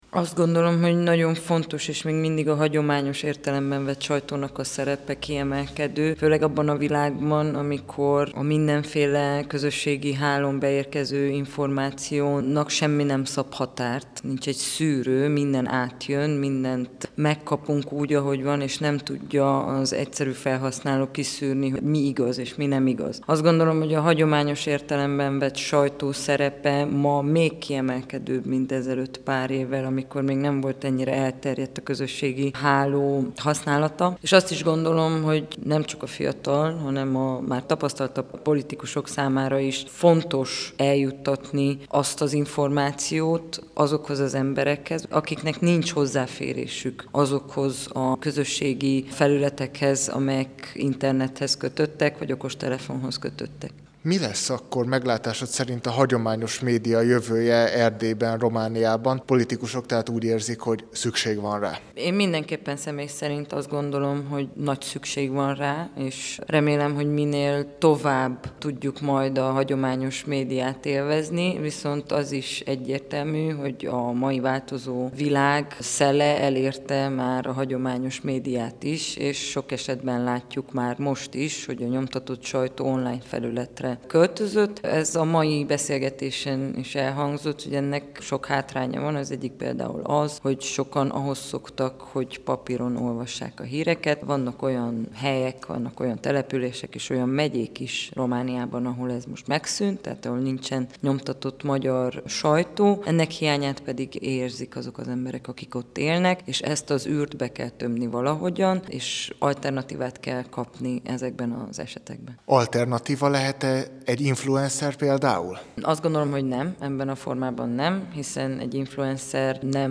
Kerekasztal-beszélgetés a politikum és a sajtó között